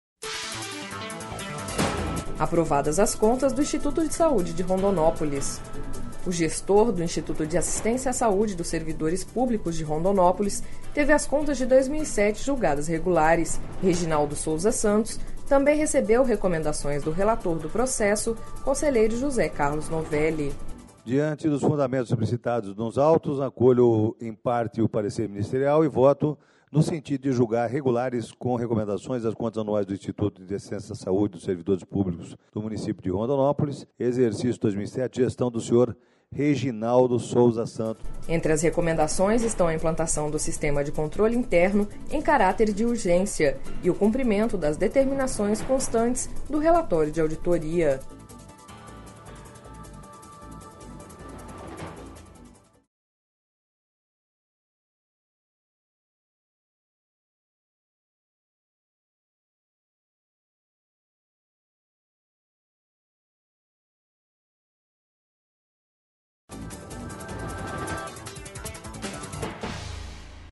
Sonora: José Carlos Novelli – conselheiro do TCE-MT